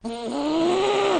Angry Noises Botón de Sonido